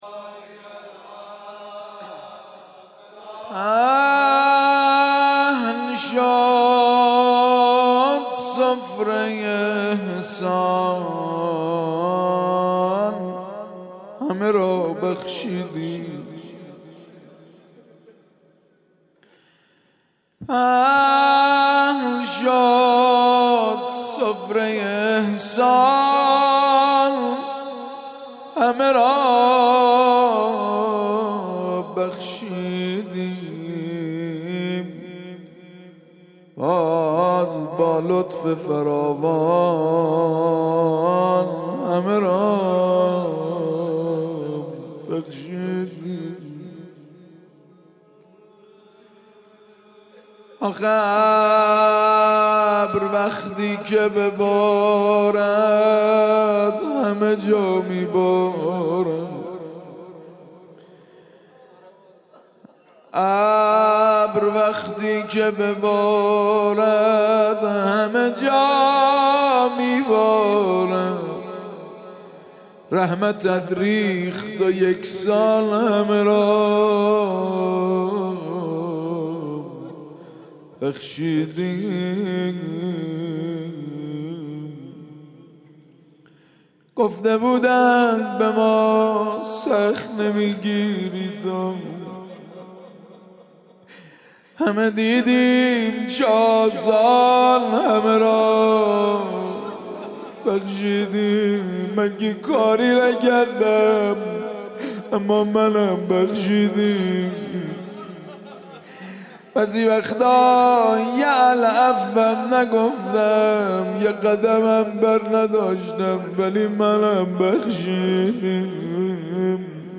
بخش اول :غزل بخش دوم:مناجات دعای کمیل بخش سوم:روضه لینک کپی شد گزارش خطا پسندها 0 اشتراک گذاری فیسبوک سروش واتس‌اپ لینکدین توییتر تلگرام اشتراک گذاری فیسبوک سروش واتس‌اپ لینکدین توییتر تلگرام